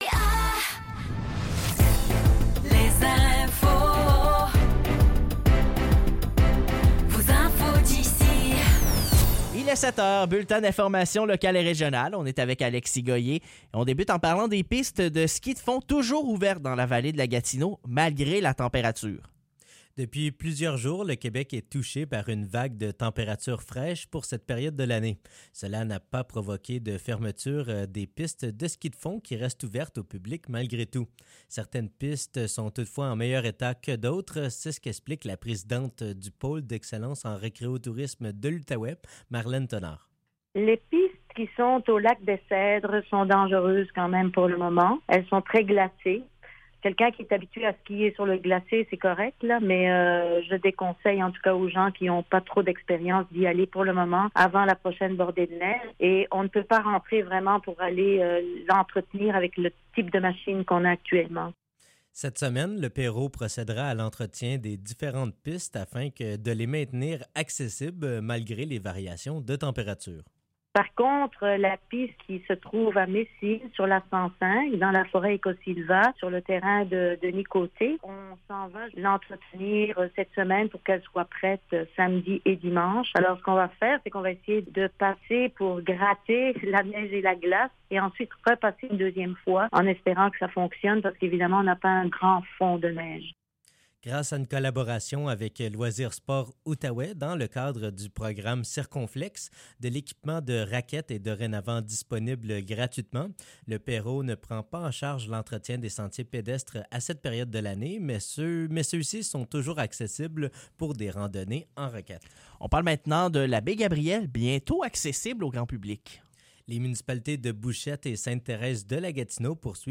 Nouvelles locales - 7 février 2024 - 7 h